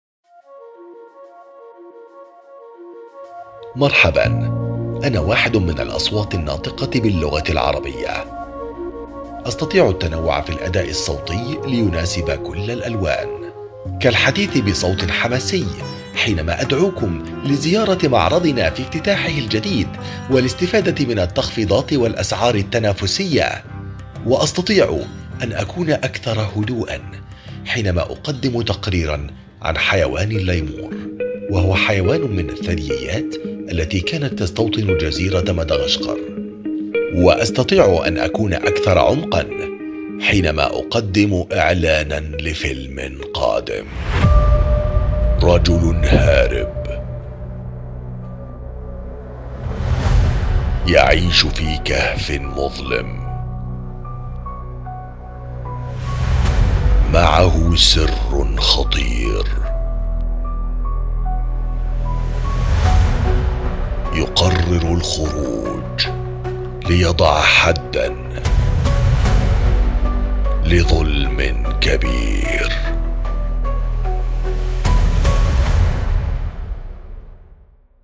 Arabic, Middle Eastern (Egypt, Saudi Arabia, Kuwait), Male, Studio, 30s-50s